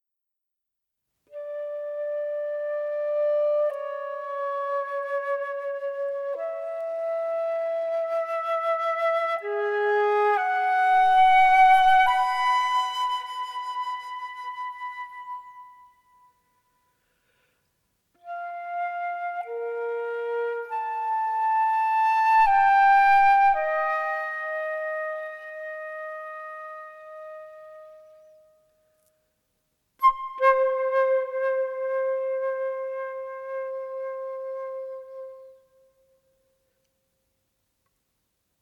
Камерно-инструментальная музыка 910.86 KB